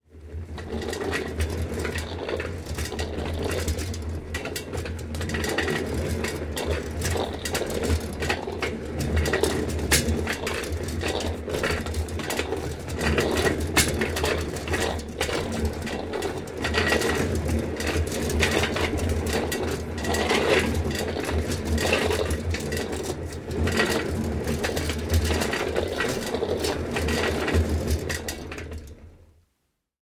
Звуки вагонетки
Шум колес вагонетки в шахте